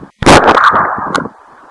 Guest EVPs
"The Sound Of A Gunshot "
She was recording in a section of a cemetery where many war vetererns are buried when she captured this very clear evp of a gunshot.